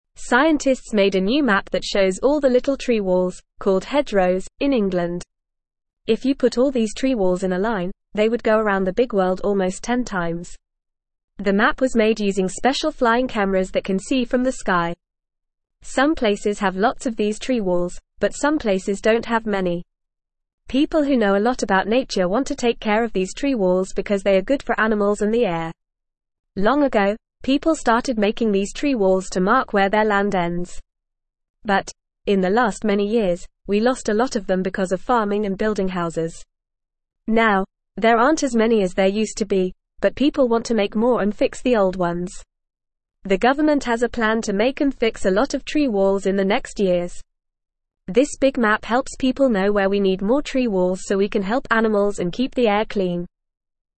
Fast
English-Newsroom-Beginner-FAST-Reading-New-Map-Shows-Tree-Walls-in-England.mp3